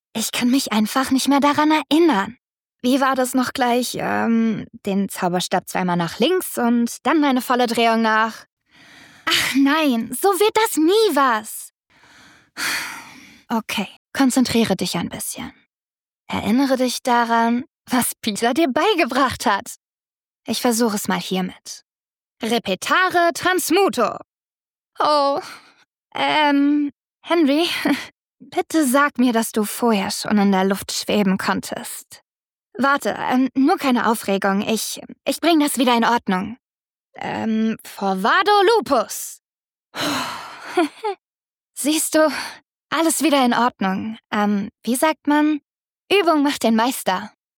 Unsicher im Umgang mit ihren Kräften. Verzweifelt und überfordert, da sie versehentlich Henry verzaubert.